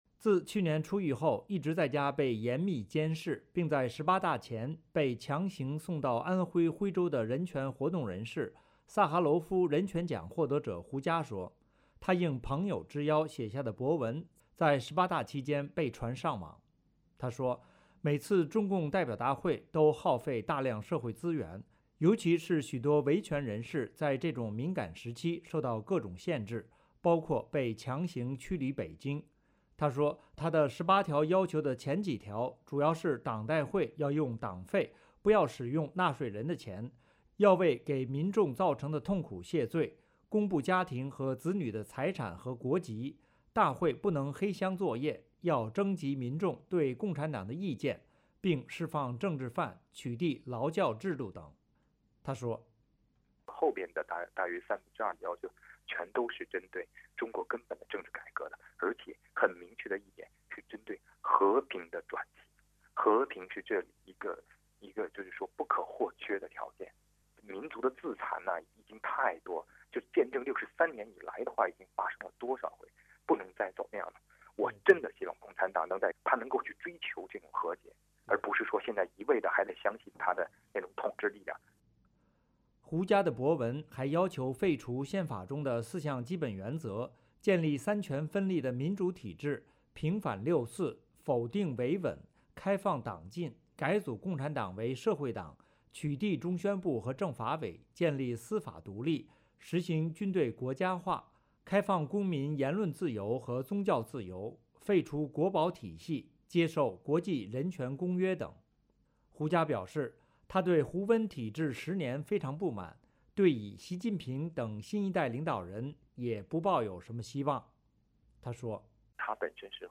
一向敢言的中国知名人权活动人士胡佳在中共十八大前夕提出的对十八大的十八条要求，近日被发到网上。胡佳星期三在接受美国之音记者采访时表示，他主要是希望中共能够通过平和的过程，放弃一党专政，成为真正意义的执政党，让中国走上民主的道路。